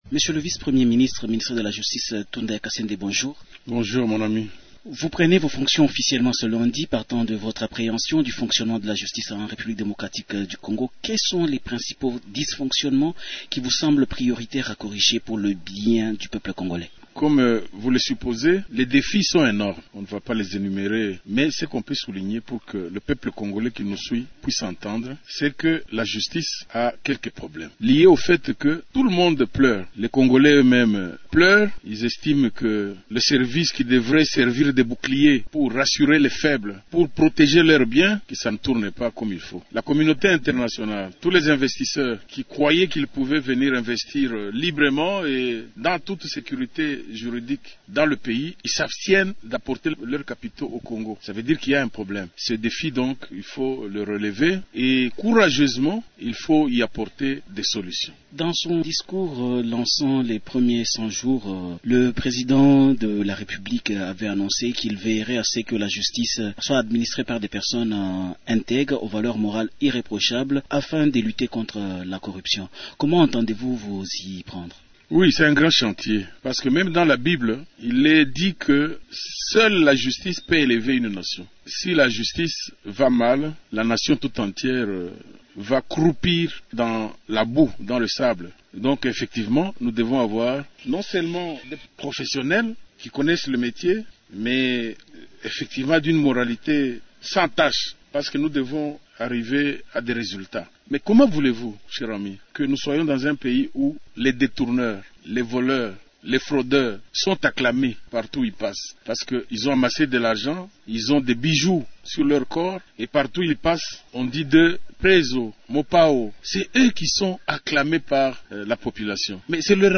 Célestion Tunda Ya Kasende parle des défis de la justice congolaise dans cet entretien